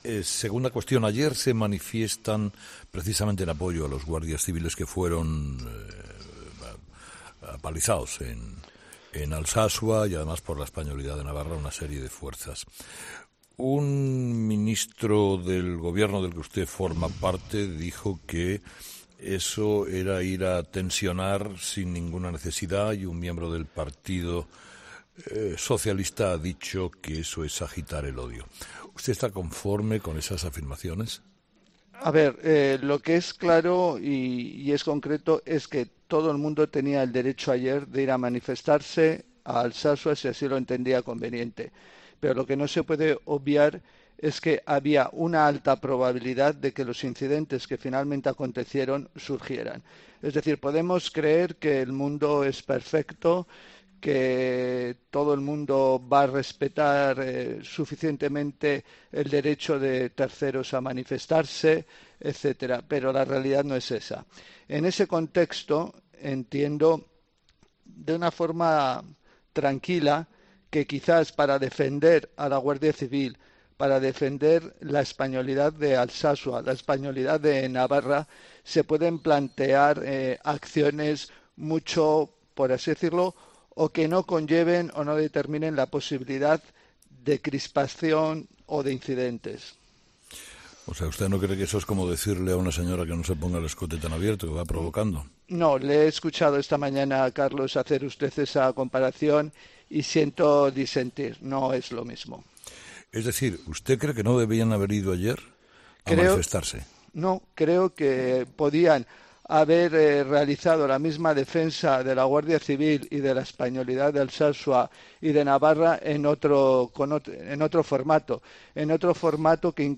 Entrevista con F. Grande Marlaska
Entrevistado: "Fernando Grande Marlaska"
En la entrevista de hoy en 'Herrera en COPE', el comunicador y el ministro han protagonizado momentos de pura radio debatiendo sobre este asunto.